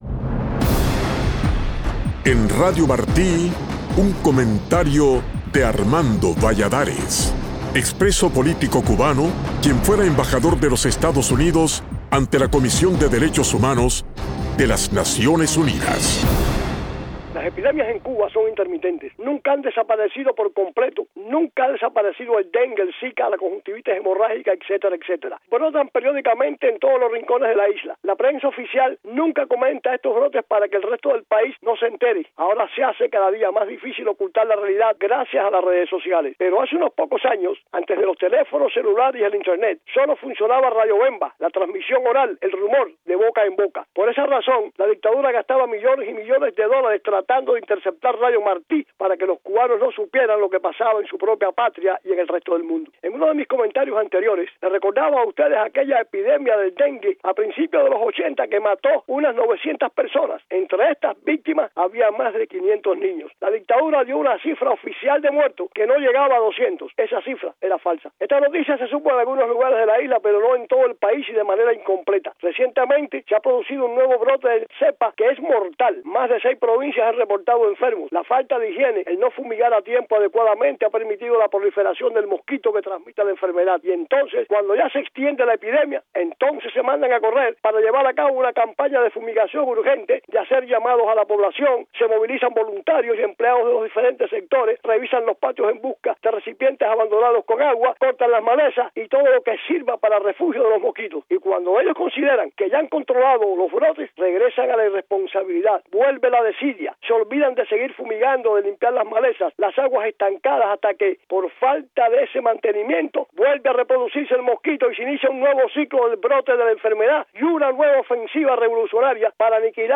Embajador Valladares: Mi opinión